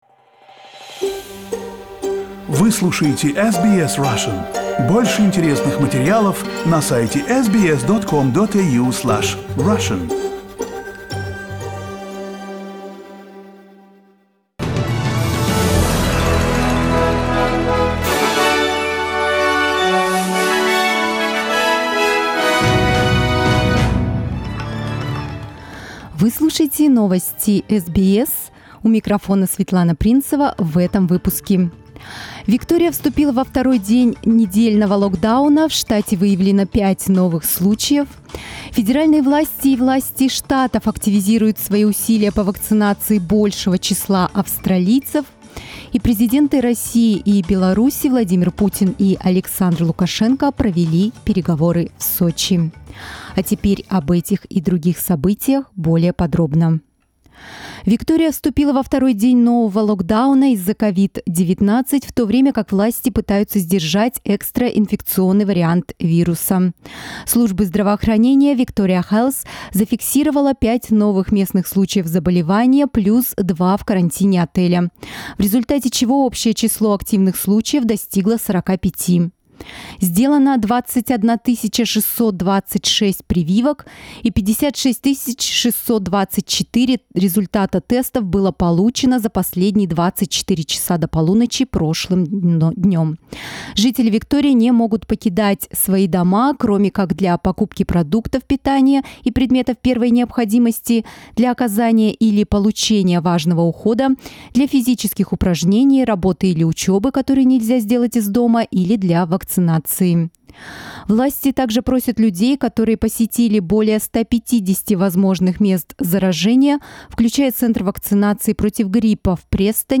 Новости SBS на русском языке - 29.05